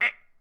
capri_jump2.ogg